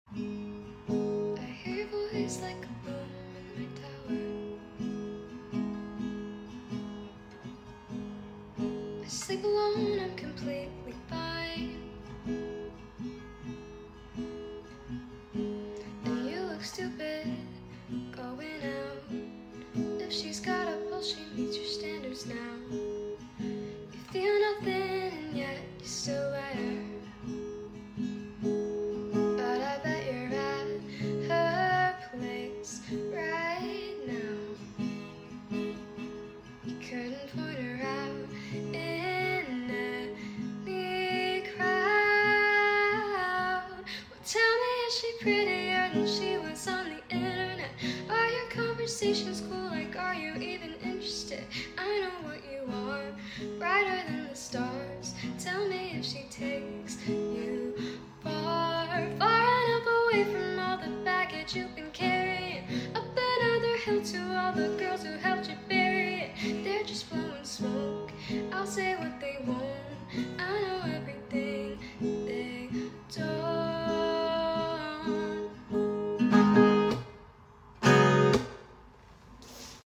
featuring my wildly mediocre guitar